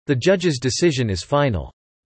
※英語音声には音読さんを使用しています。